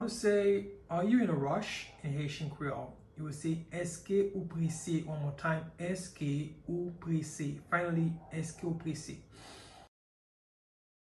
“Èske ou prese?” Pronunciation in Haitian Creole by a native Haitian can be heard in the audio here or in the video below:
Are-you-in-a-rush-in-Haitian-Creole-Eske-ou-prese-pronunciation-by-a-Haitian-teacher.mp3